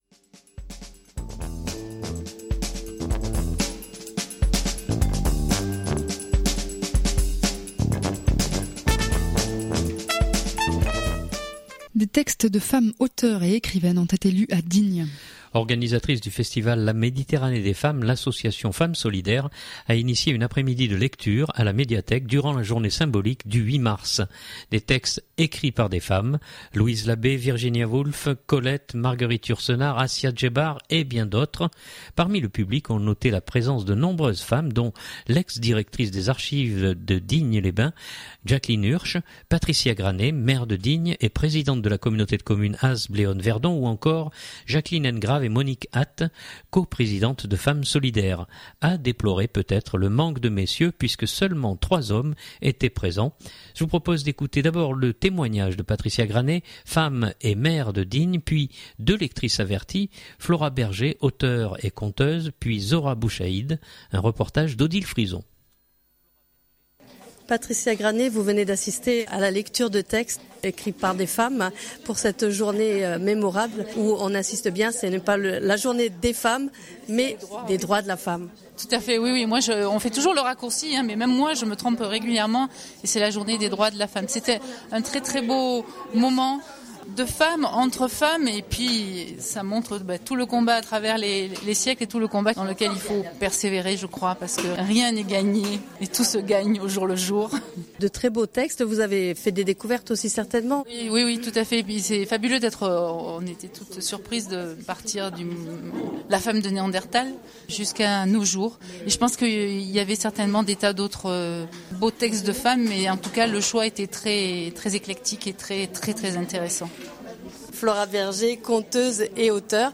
Des textes de femmes auteures et écrivaines ont été lus à Digne.
Organisatrice du festival La Méditerranée des Femmes, l’association Femmes Solidaires initiait une après-midi de lectures à la médiathèque durant la journée symbolique du 8 mars. Des textes écrits par des femmes : Louise Labé, Virginia Woolf, Colette, Marguerite Yourcenar, Assia Djebar et bien d’autres.